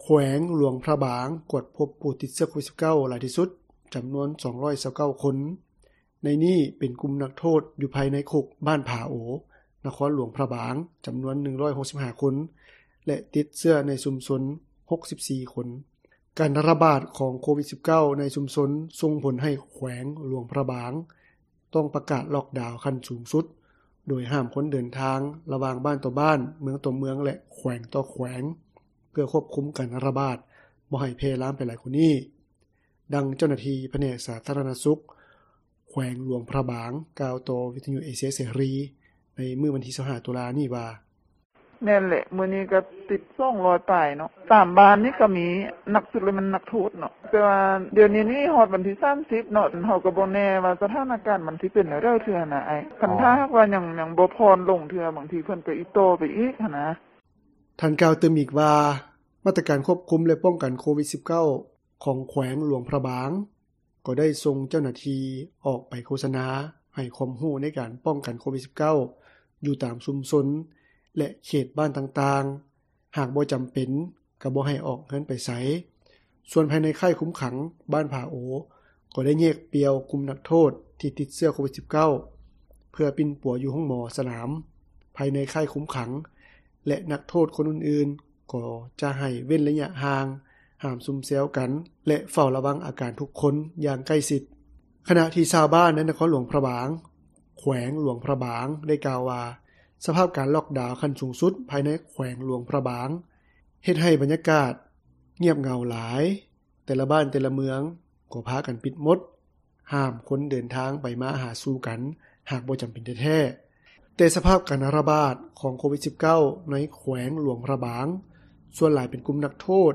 ແຂວງຫຼວງພຣະບາງ ກວດພົບຜູ້ຕິດເຊື້ອໂຄວິດ-19 ຫຼາຍທີ່ສຸດ ຈຳນວນ 229 ຄົນ. ໃນນີ້, ເປັນກຸ່ມນັກໂທດ ຢູ່ພາຍໃນຄຸກບ້ານຜາໂອ ນະຄອນຫຼວງພຣະບາງ ຈຳນວນ 165 ຄົນ ແລະ ຕິດເຊື້ອໃນຊຸມຊົນ 64 ຄົນ. ຕໍ່ການຣະບາດ ຂອງໂຄວິດ-19 ໃນຊຸມຊົນນີ້, ສົ່ງຜົລໃຫ້ແຂວງຫຼວງພຣະບາງ ຕ້ອງປະກາດລ໊ອກດາວ ຂັ້ນສູງສຸດ, ໂດຍຫ້າມຄົນເດີນທາງ ລະຫວ່າງບ້ານຕໍ່ບ້ານ, ເມືອງຕໍ່ເມືອງ ແລະ ແຂວງຕໍ່ແຂວງ ເພື່ອຄວບຄຸມການຣະບາດ ບໍ່ໃຫ້ແຜ່ລາມໄປຫຼາຍກວ່ານີ້. ດັ່ງເຈົ້າໜ້າທີ່ ສາທາຣະນະສຸຂ ແຂວງຫຼວງພຣະບາງ ກ່າວຕໍ່ວິທຍຸເອເຊັຽເສຣີ ໃນມື້ວັນທີ 25 ຕຸລາ ນີ້ວ່າ:
ດັ່ງຊາວລາວ ຜູ້ນຶ່ງ ໃນນະຄອນຫຼວງພຣະບາງ ແຂວງຫຼວງພຣະບາງ ກ່າວຕໍ່ວິທຍຸເອເຊັຽເສຣີ ໃນມື້ດຽວກັນນີ້ວ່າ:
ຊາວລາວອີກຜູ້ນຶ່ງ ໃນນະຄອນຫຼວງວຽງຈັນ ກ່າວຕໍ່ວິທຍຸເອເຊັຽເສຣີ ໃນມື້ດຽວກັນນີ້ວ່າ ການຣະບາດ ຂອງໂຄວິດ-19 ໃນຊຸມຊົນ ຍັງເພີ່ມຂຶ້ນຫຼາຍ, ແຕ່ກະຍັງສາມາດໄປຈັບຈ່າຍຊື້ເຄື່ອງ ຊື້ຂອງໃຊ້ຈຳເປັນໄດ້ຢູ່, ແຕ່ຫ້າມອອກໄປຊຸມແຊວບ່ອນອື່ນ.